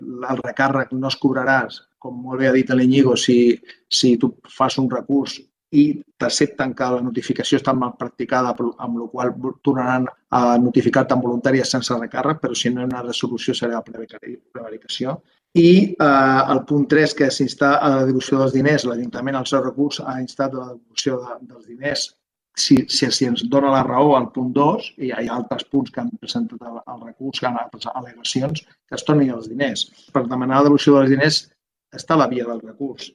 Javier Sánchez, regidor PSC